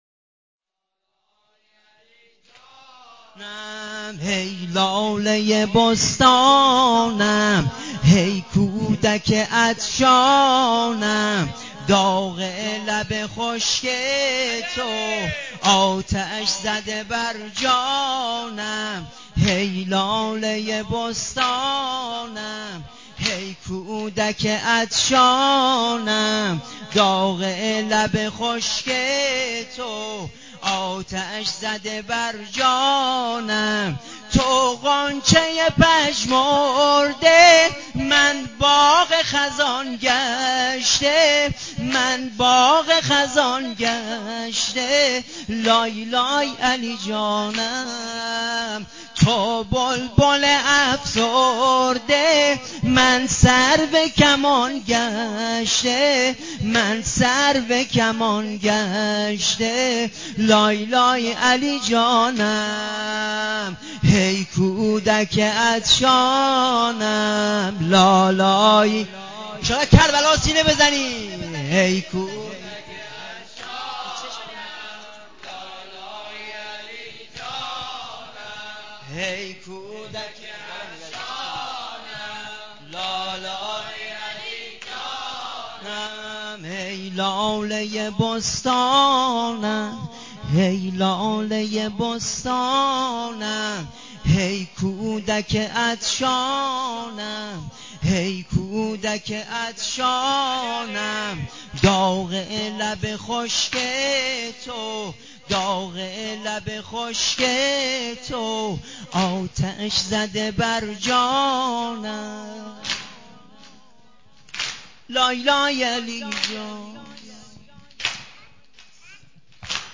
نوحه سینه زنی شب هفتم محرم ۹۵